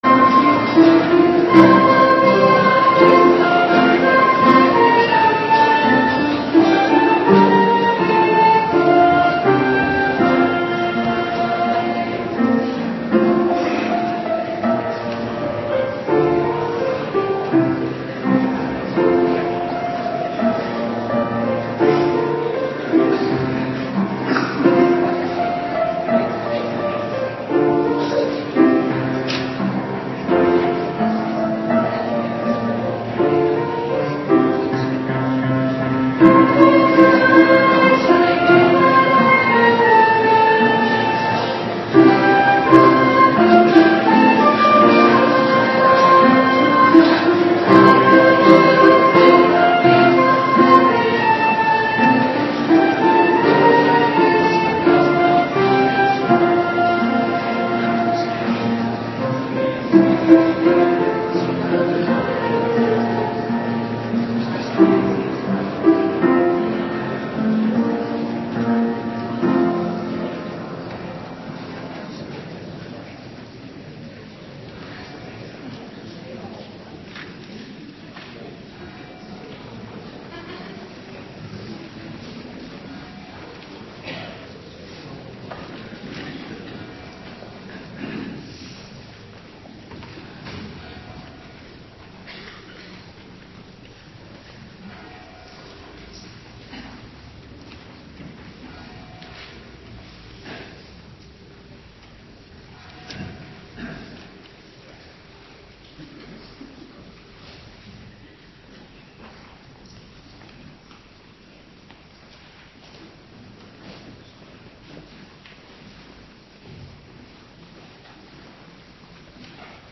Morgendienst 19 april 2026